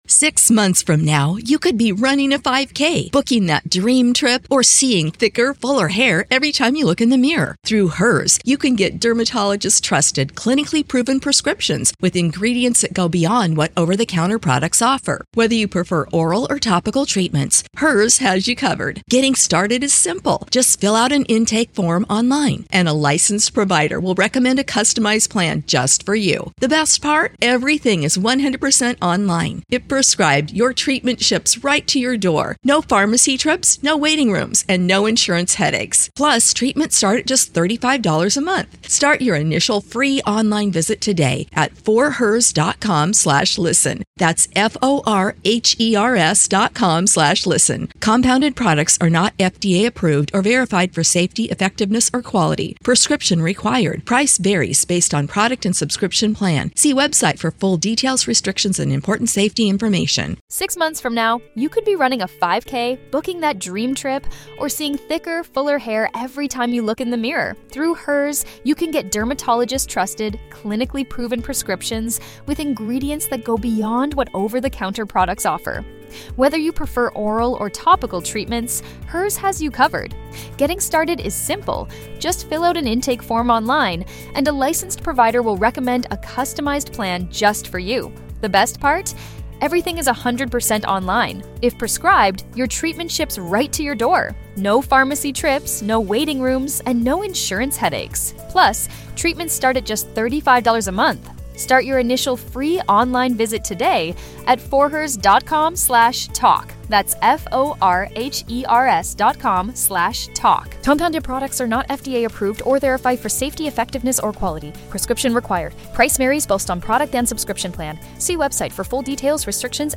Each episode of "White Noise Wednesdays" features a soothing blend of gentle sounds, including the soothing hum of white noise and calming nature sounds.